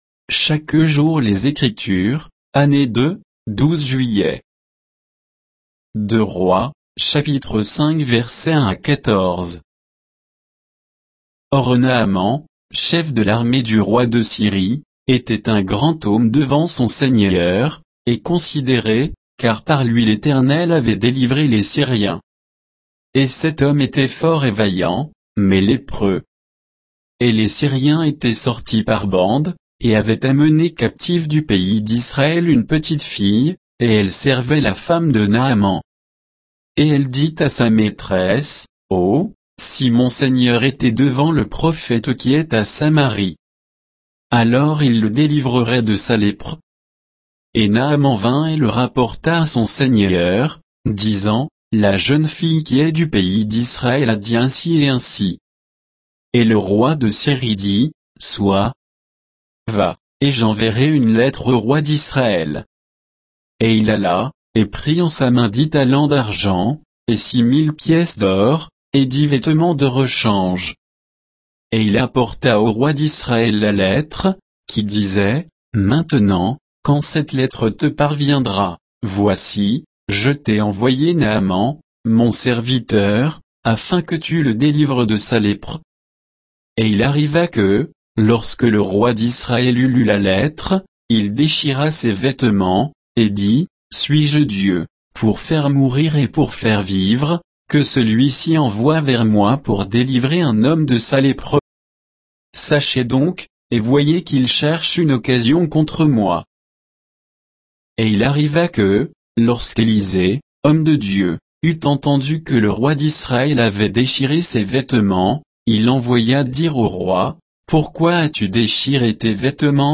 Méditation quoditienne de Chaque jour les Écritures sur 2 Rois 5, 1 à 14